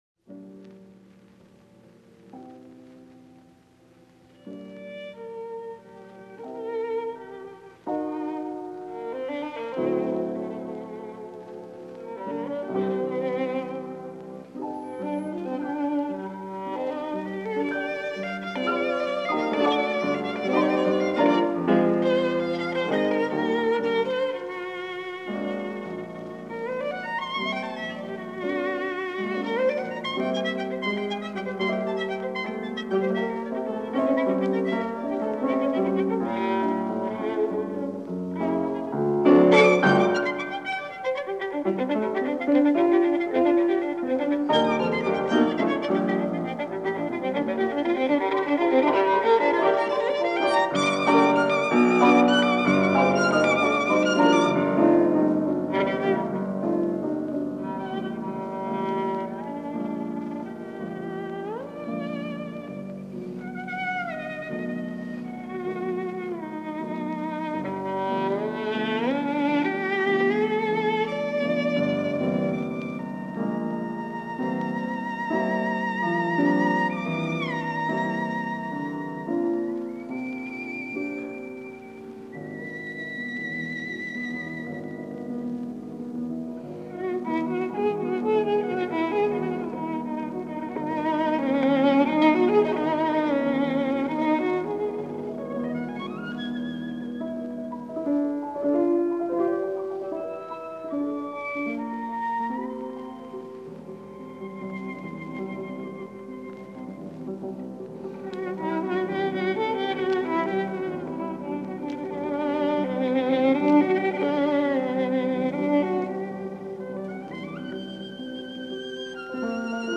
hegedű/violin
zongora/piano.